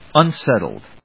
音節un・set・tled 発音記号・読み方
/`ʌnséṭld(米国英語), ʌˈnsetʌld(英国英語)/